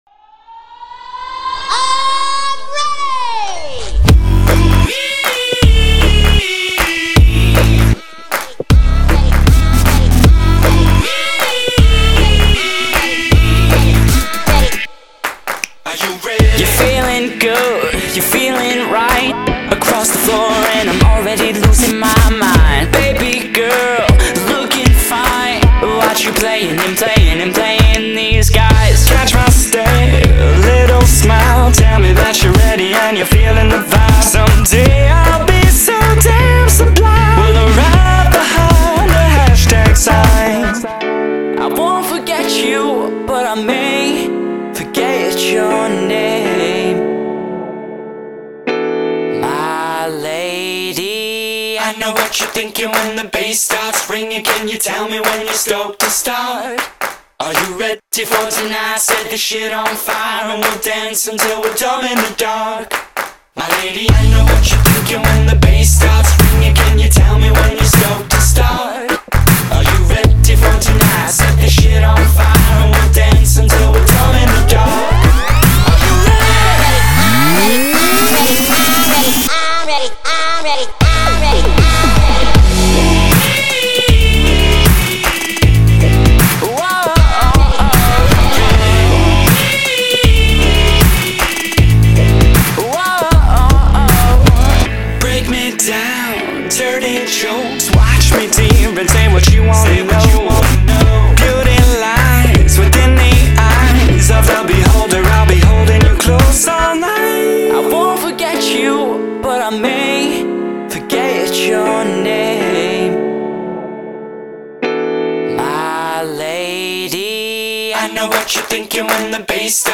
это энергичная трек в жанре поп и EDM